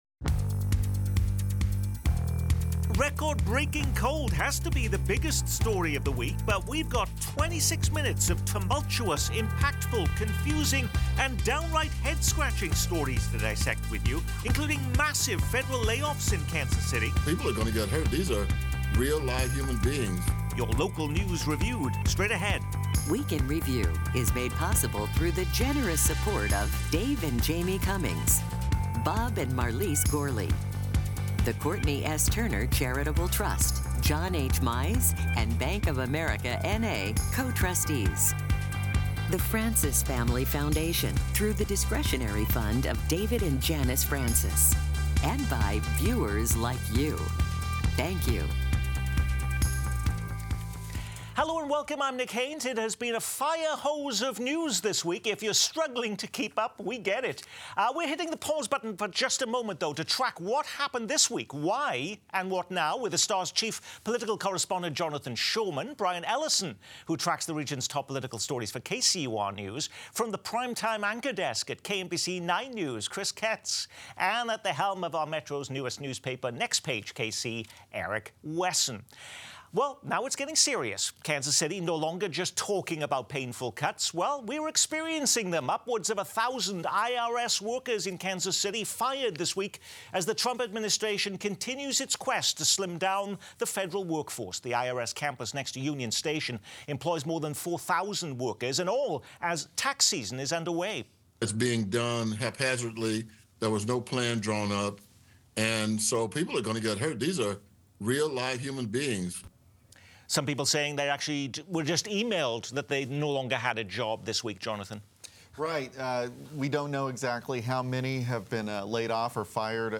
Kansas City Week In Review offers an in-depth view on the top stories of the week with newsmakers and guest experts sharing their insight and perspective.